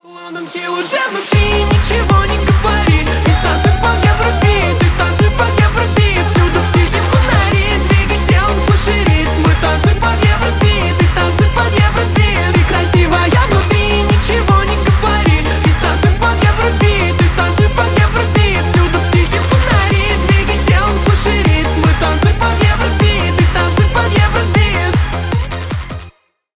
Поп Музыка # Танцевальные
клубные # весёлые